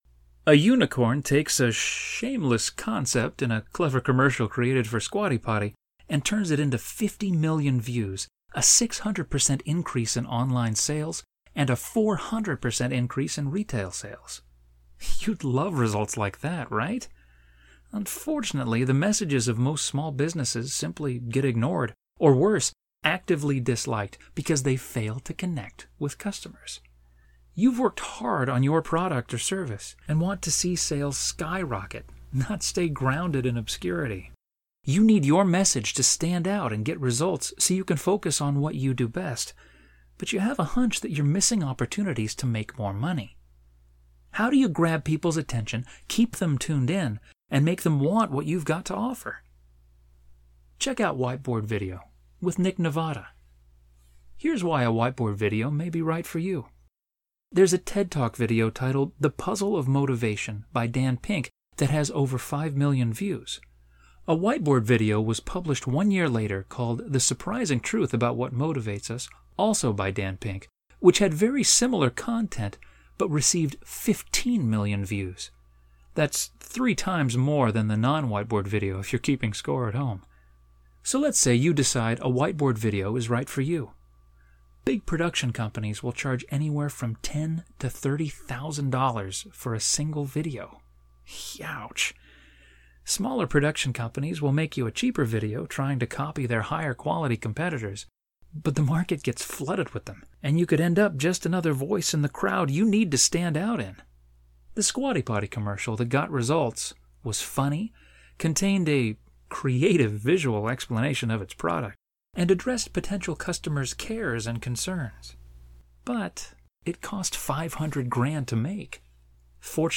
Male
Adult (30-50)
Voice Over For Web Ad
0502Whiteboad_Ad_VO_Updated.mp3